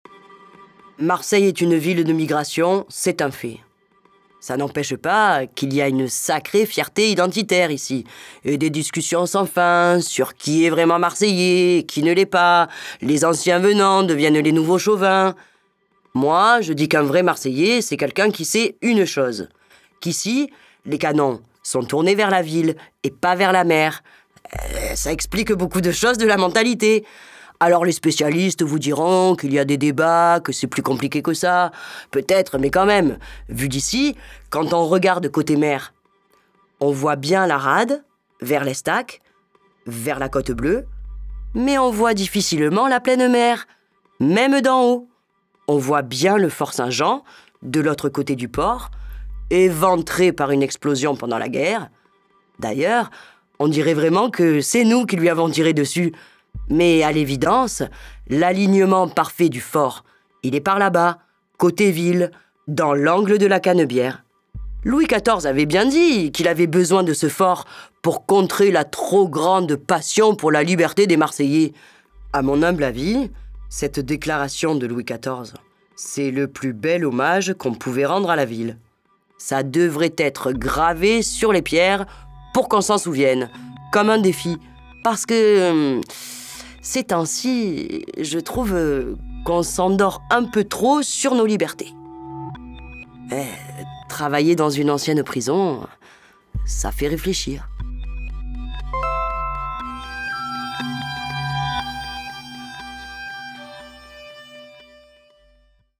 En choisissant de confier son évocation à une intervenante civile, simple promeneuse de chiens, cette fiction sonore, incarnée à hauteur humaine, accompagnera votre déambulation en tâchant de lever le voile sur ces tabous que protègent les murailles qui se dressent au cœur de la ville.
Une fiction sonore disponible depuis mai 2024 sur l’application dédiée – GOH
Accordéon et Vielle à roue